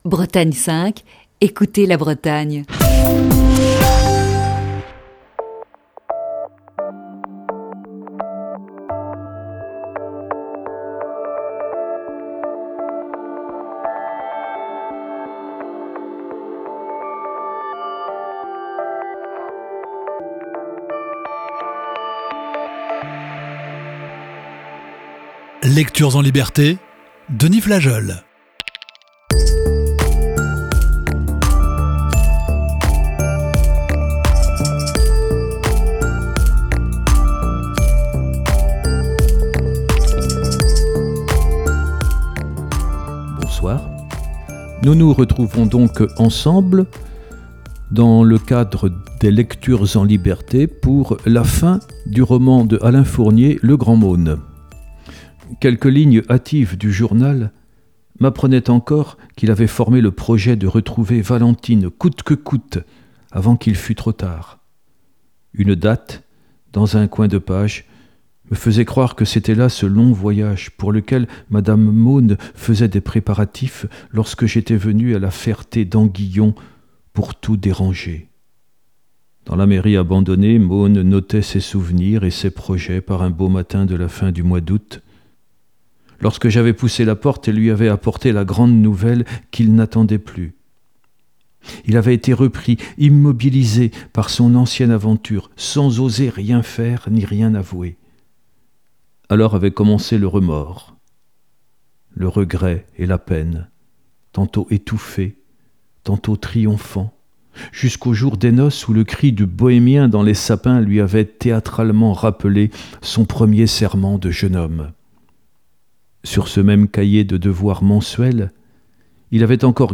Émission du 31 janvier 2020. Cette semaine dans Lecture(s) en liberté